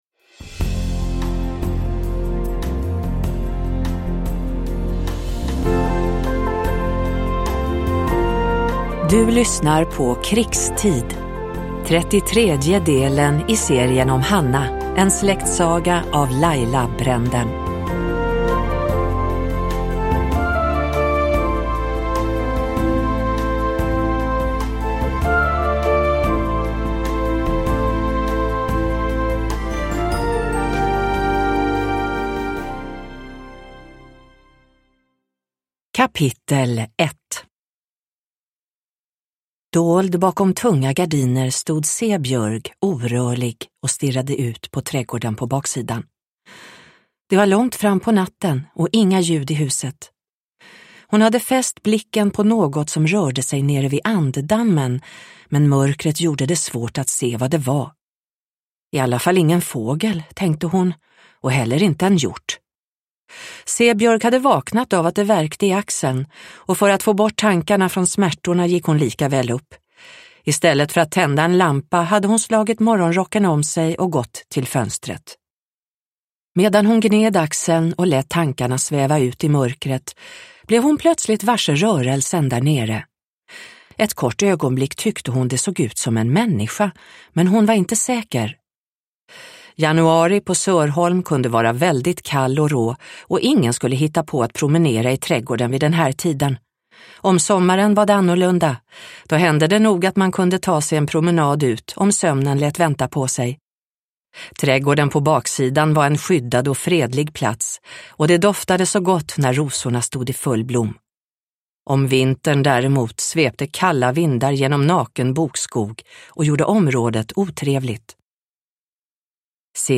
Krigstid – Ljudbok